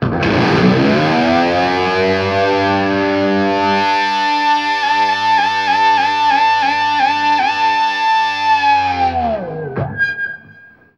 DIVEBOMB 2-L.wav